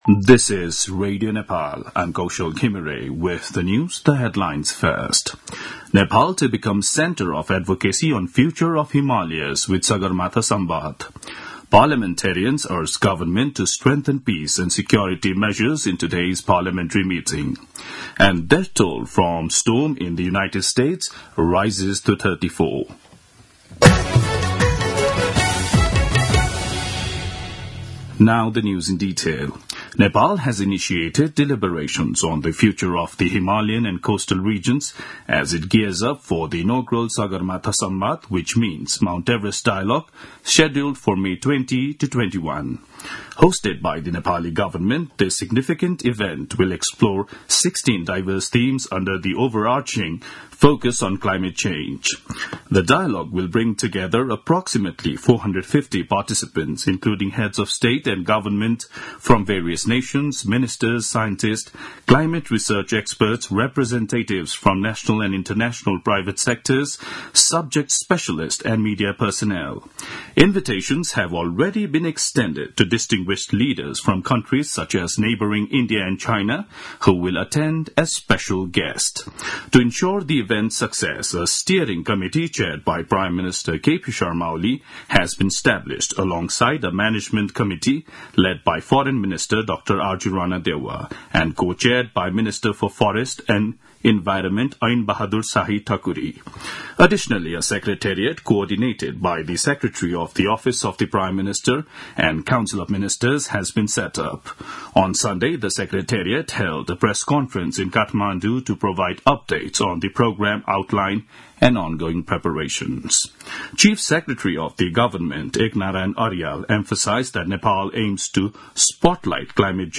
दिउँसो २ बजेको अङ्ग्रेजी समाचार : ४ चैत , २०८१
2-pm-news-3.mp3